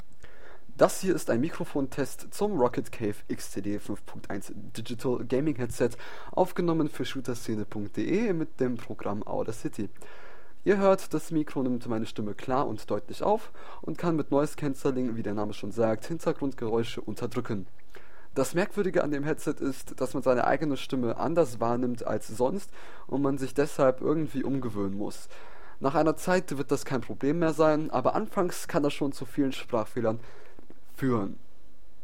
Beim Mikrofon gibt es gar nichts zu bemängeln. Das Mikro nimmt die eigene Stimme klar und deutlich auf und kann mit Noise-Cancelling – wie der Name schon sagt – Hintergrundgeräusche unterdrücken.
Roccat-Kave-XTD-Mikrofontest.mp3